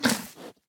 哞菇：进食
玩家喂食棕色哞菇时随机播放这些音效
Minecraft_mooshroom_eat2.mp3